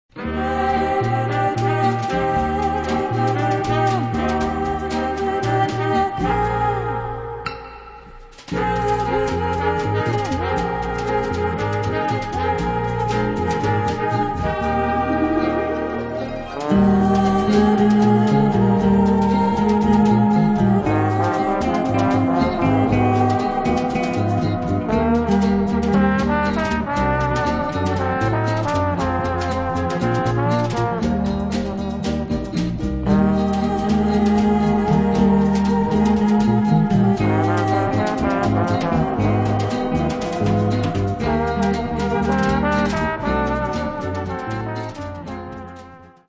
Zwei Soundtracks zu deutschen Aufklärungsfilmen (1968/69)